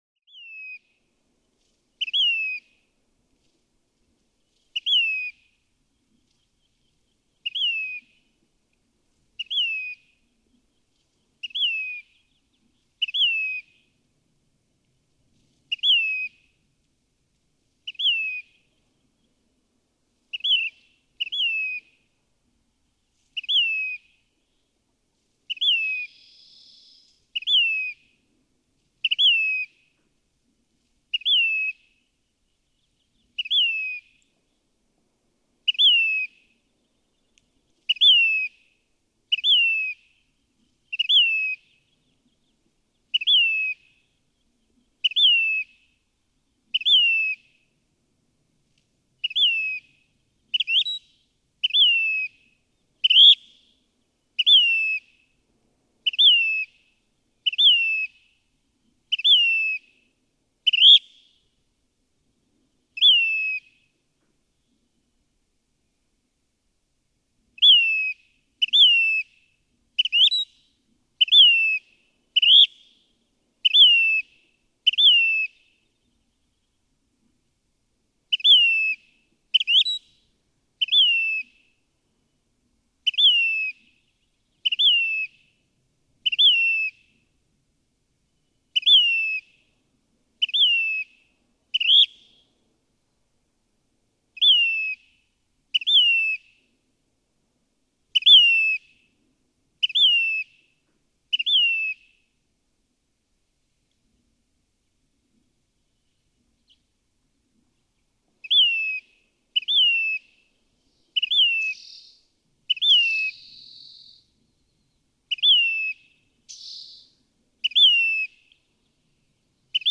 Say's phoebe
Just after sunrise, the dawn chorus largely finished, this male continues to use his three different songs, but with far less vigor.
Smoke Tree Canyon, Anza-Borrego Desert State Park, Borrego Springs, California.
385_Say's_Phoebe.mp3